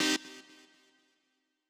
AIR Dee Stab C2.wav